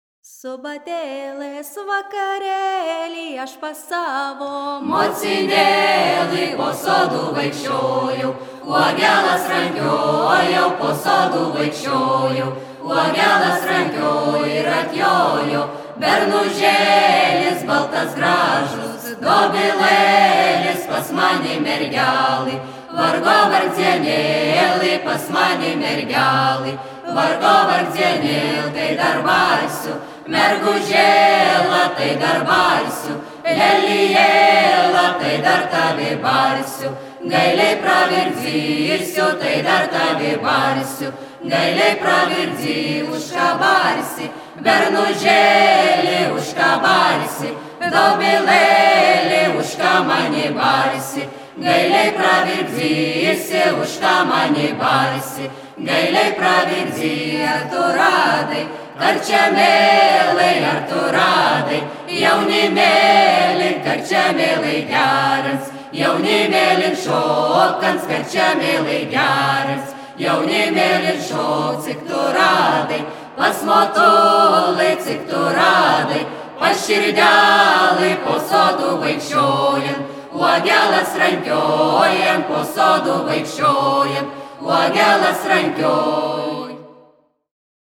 ansamblis
Merkinės kultūros namų patalpose, 2011 metais